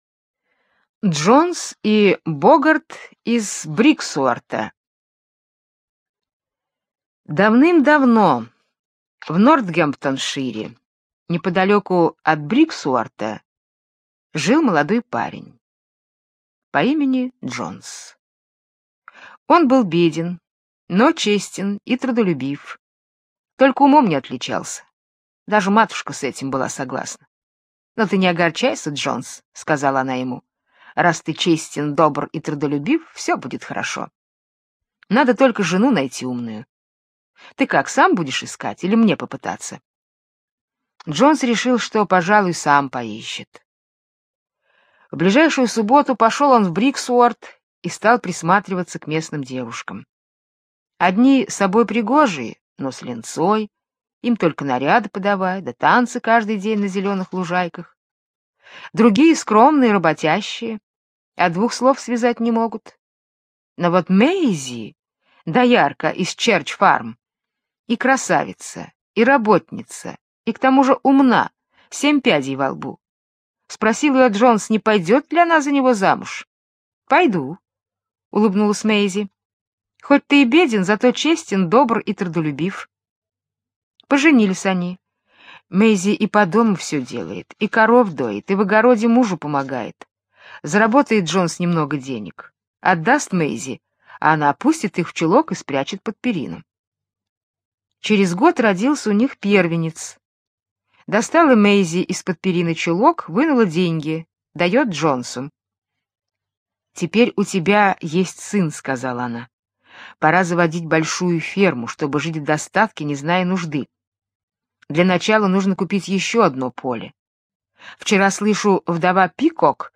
Джонс и Боггарт из Бриксуорта - британская аудиосказка - слушать онлайн